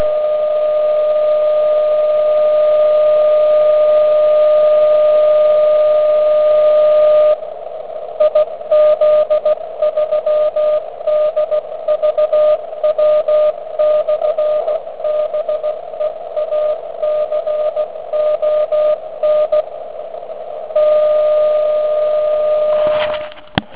Poslech jsem uskutečnil 15.12. v 6.30 SEC na ATS 3B. Maják je na 1W skutečně slyšet velice dobře.